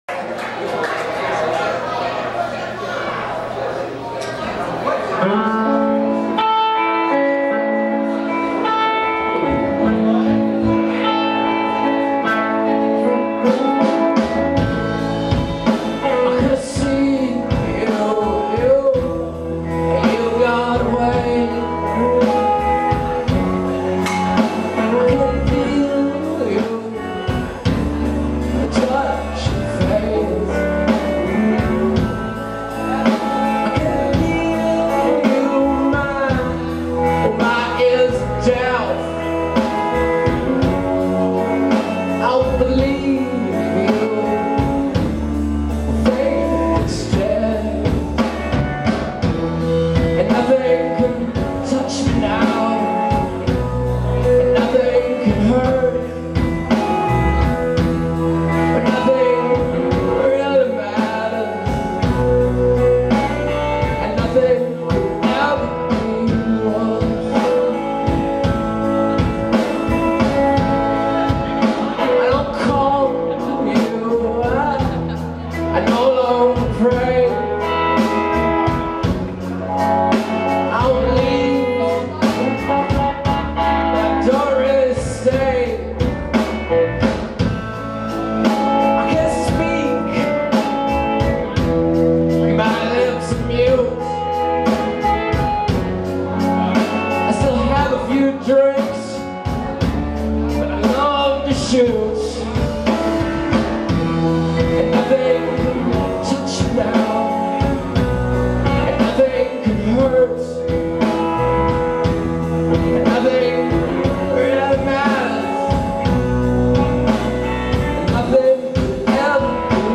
Surprising sound quality from a mini-DV camera.